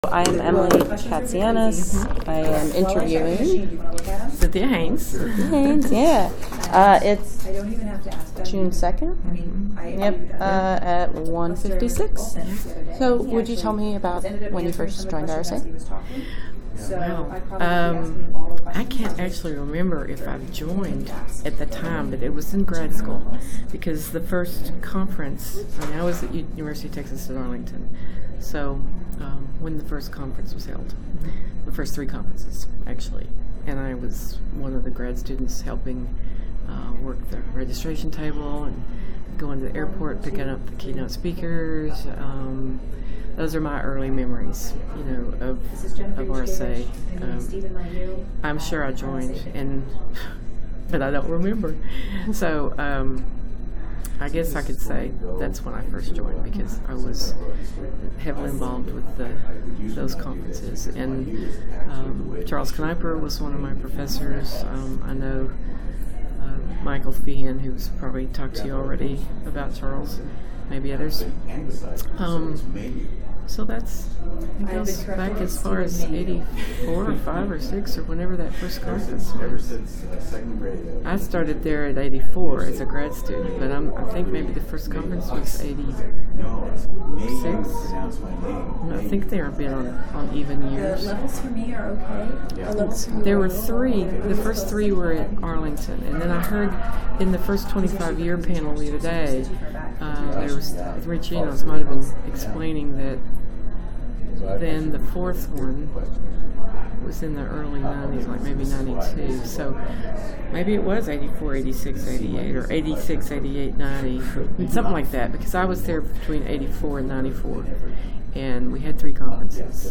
Location 2018 RSA Conference in Minneapolis, Minnesota